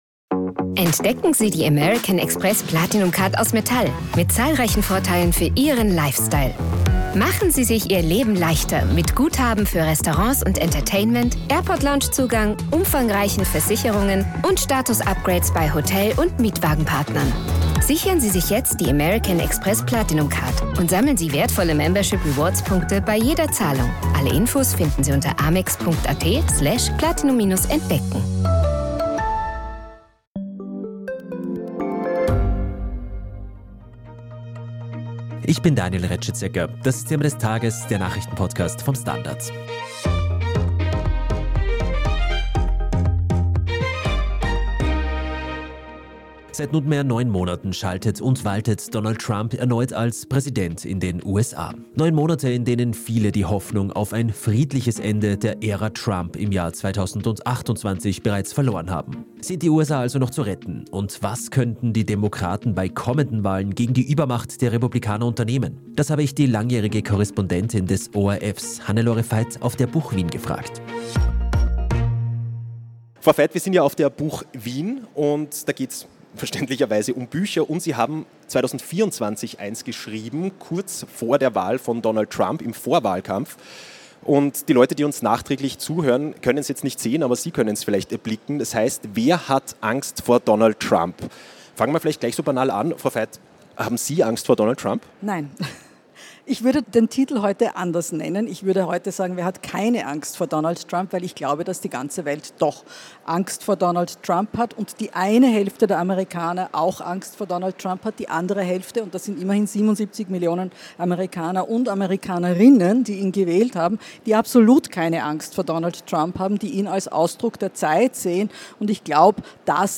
bei einem Livepodcast auf der Buch Wien.